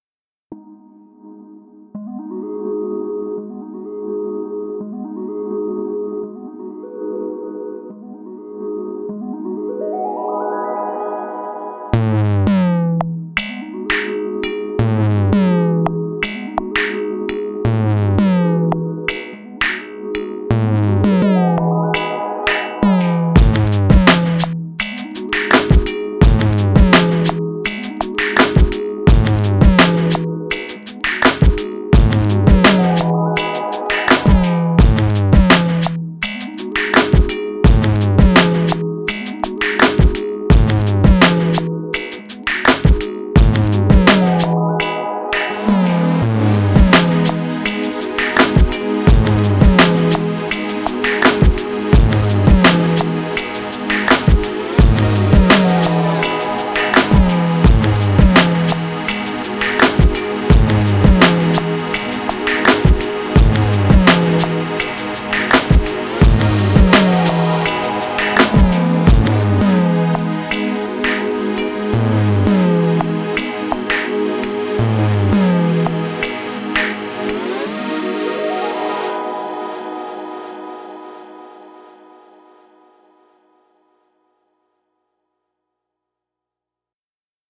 Slow dark menacing street beat with hypnotic synth pad.